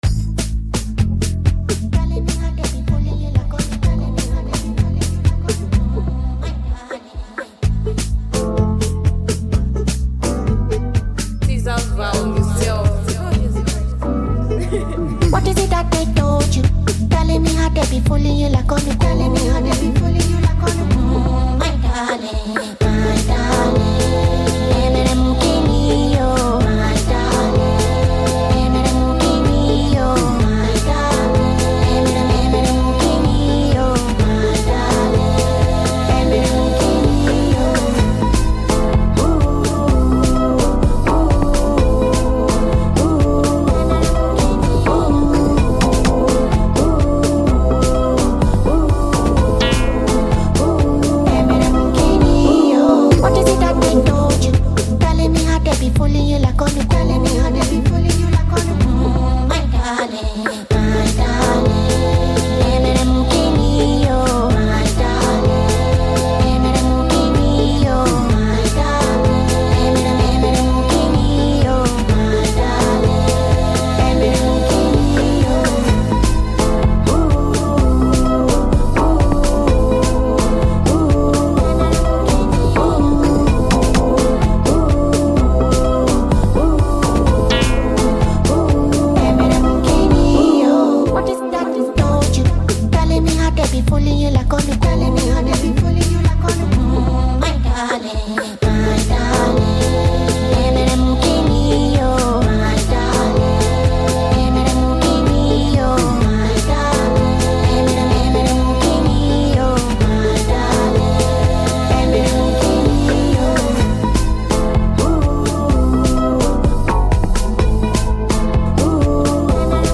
Afrobeat
seductive hit song